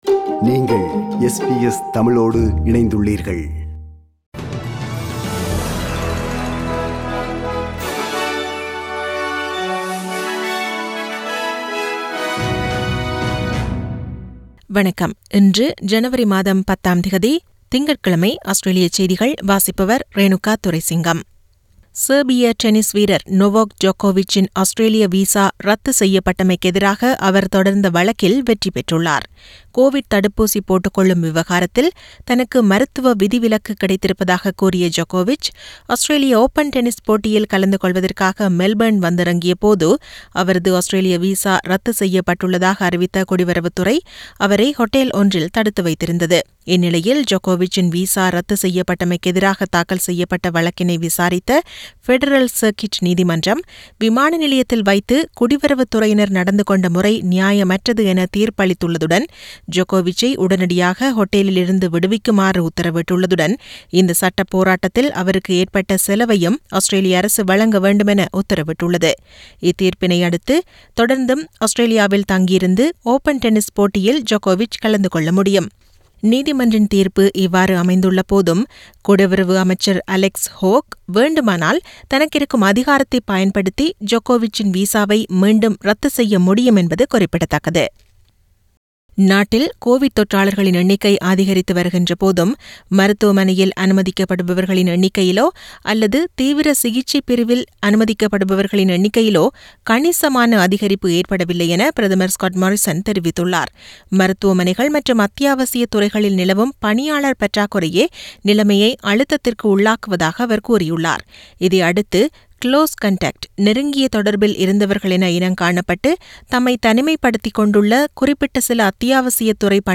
Australian news bulletin for Monday 10 Jan 2022.